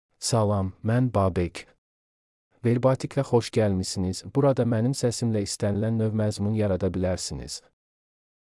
BabekMale Azerbaijani AI voice
Babek is a male AI voice for Azerbaijani (Latin, Azerbaijan).
Voice sample
Male
Babek delivers clear pronunciation with authentic Latin, Azerbaijan Azerbaijani intonation, making your content sound professionally produced.